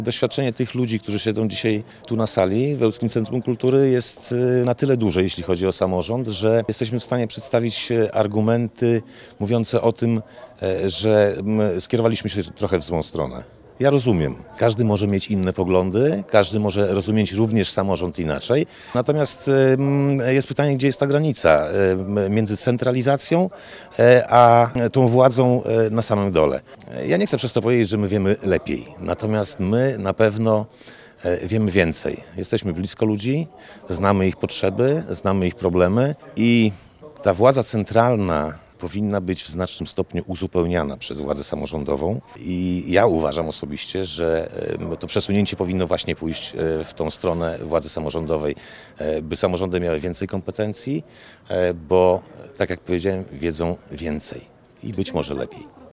-My nie sprzeciwiamy się władzy rządzącej ale nie chcemy centralizacji i uważam, że należy zachować samorządność na lokalnym poziomie – tłumaczy Andrzej Bezdziecki, wójt gminy Kalinowo.